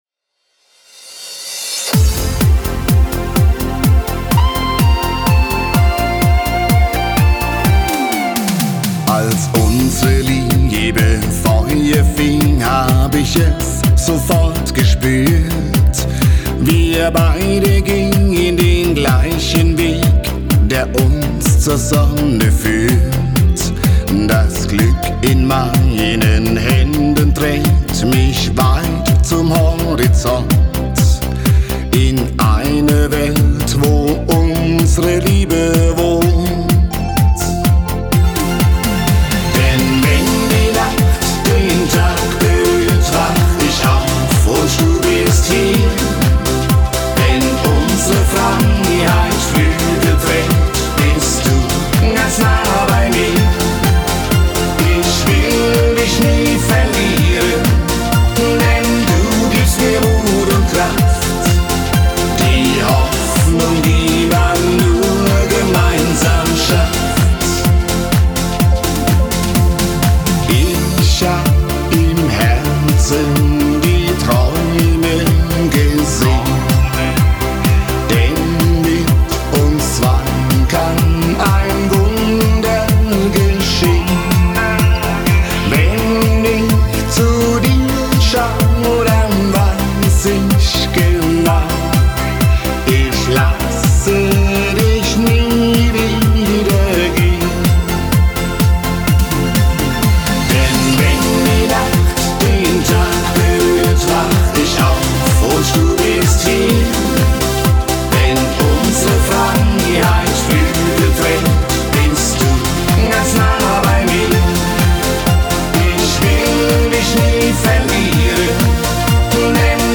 Disco-Fox